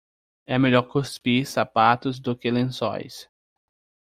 Pronounced as (IPA) /kusˈpi(ʁ)/